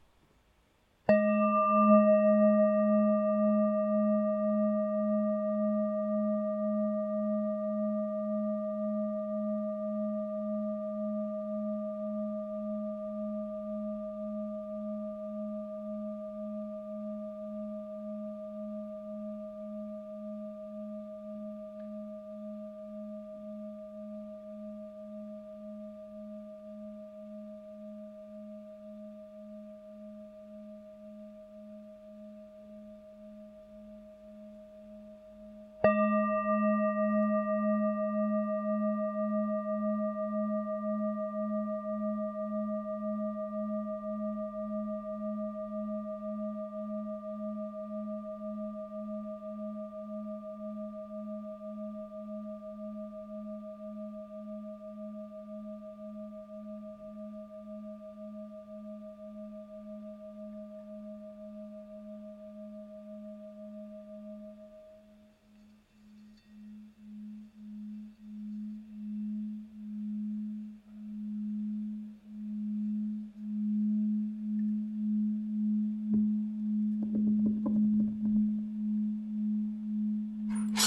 Campana Tibetana in lega dei 7 metalli Nota SOL(G) #3 212 HZ – R203/C per meditazione sonora
Campana tibetana contemporanea , creata con l’antica tradizione tibetana in lega dei 7 metalli.
Nota Armonica  RE(D) #5 626 HZ
Nota di fondo SOL(G) #3 212 HZ